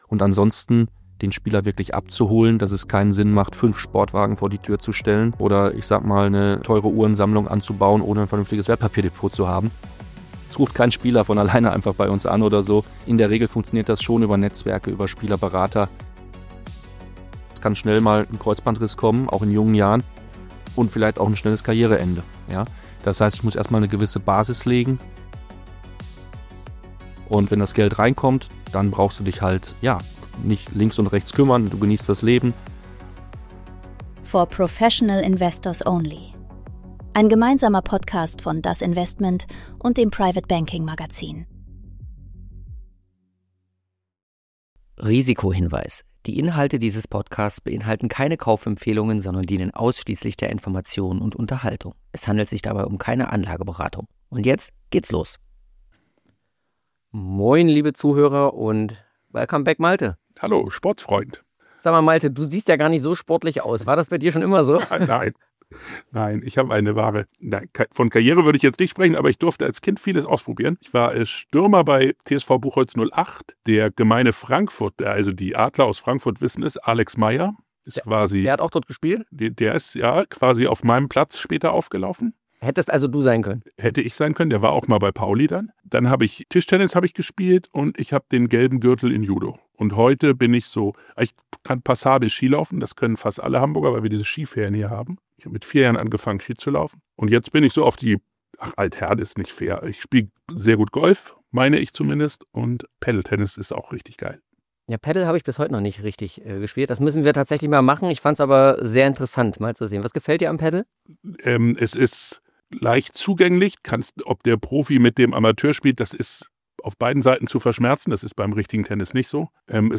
Ein ehrliches, manchmal unerwartetes Gespräch über das kurze Fenster, in dem große Karrieren über die Zukunft entscheiden.